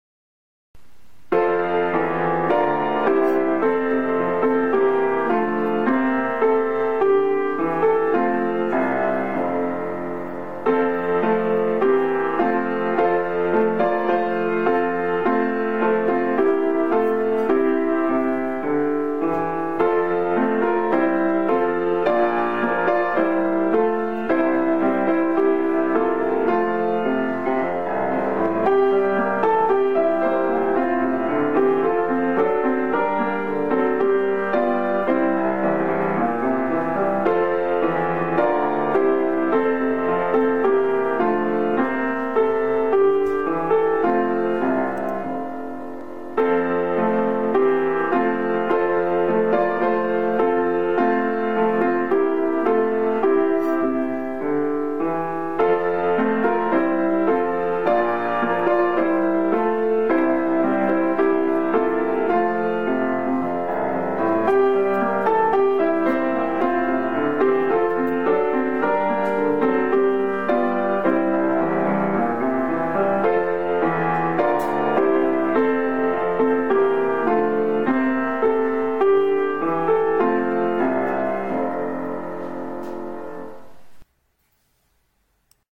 どんな きょくか きいてみて ください♪ さがしょうがっこう こうか 嵯峨小学校校歌（音のみ）